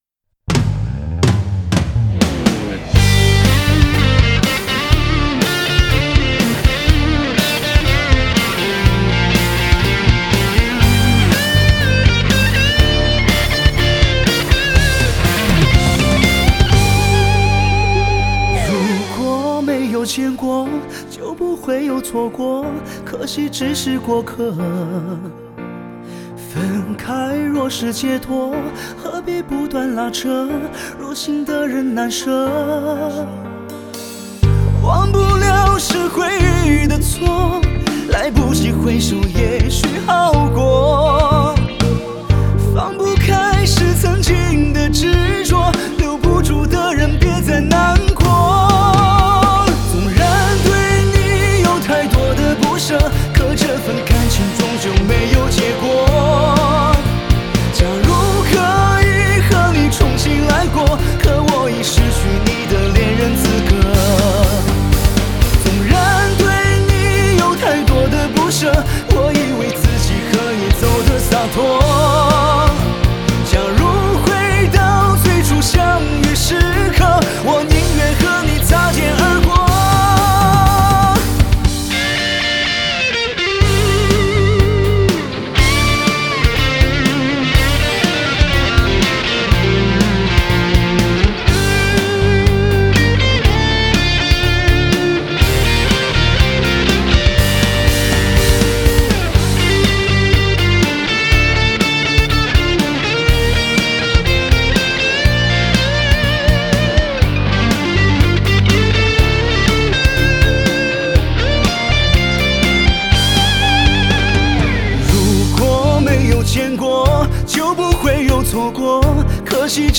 Ps：在线试听为压缩音质节选，体验无损音质请下载完整版
吉他